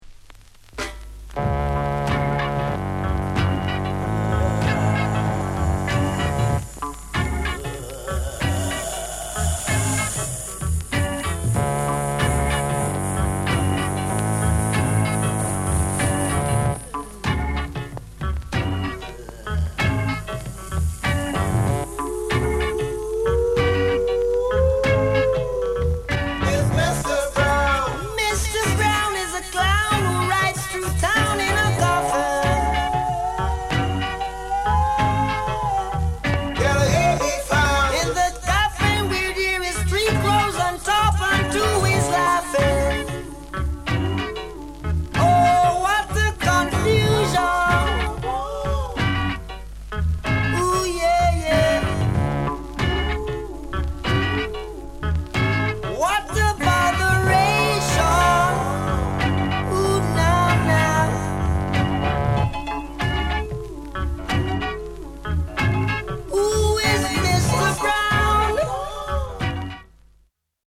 SOUND CONDITION VG(OK)